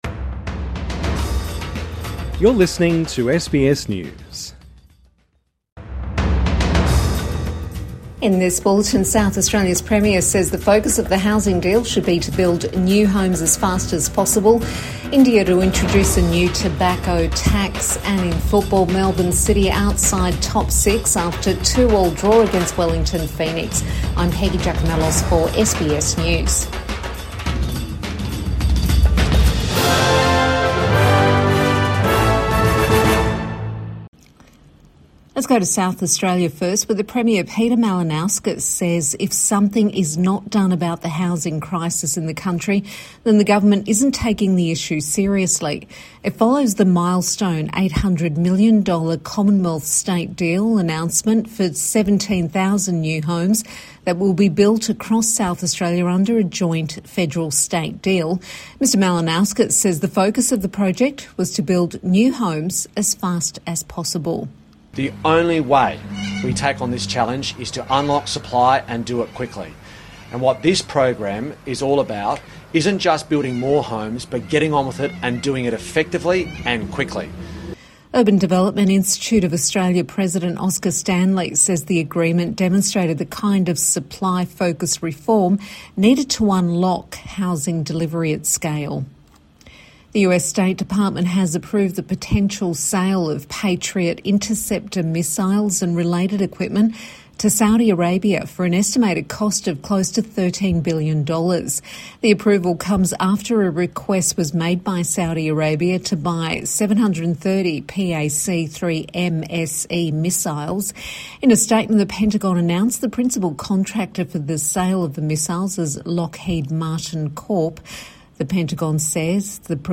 Calls for fast track SA housing deal | Evening News Bulletin 31 January 2026